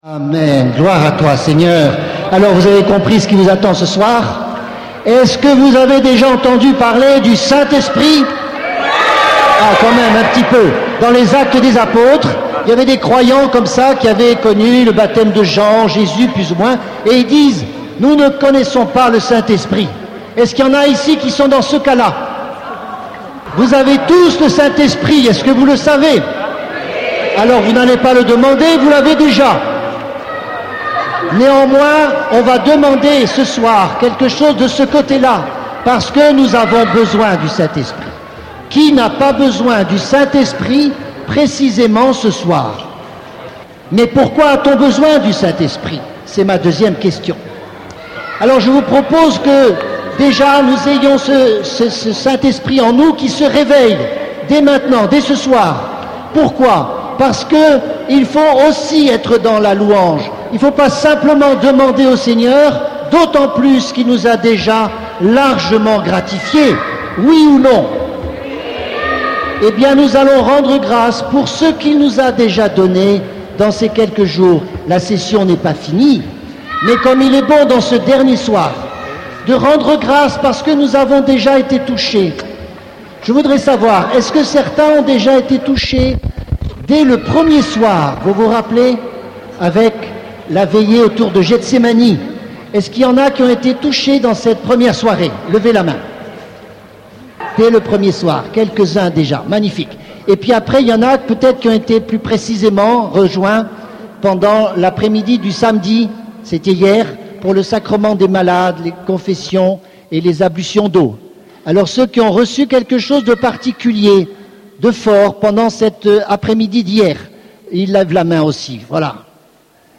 L'onction du Saint-Esprit fait la diff�rence ! (Veill�e) [ Collectif R�f: E004270 Produit original: Maria Multi M�dia AU02081] - 3.00 EUR :
Revivons cette veill�e du dimanche o� nous exultons dans le Saint-Esprit pour ce qu'il nous a donn� et souhaite nous donner encore !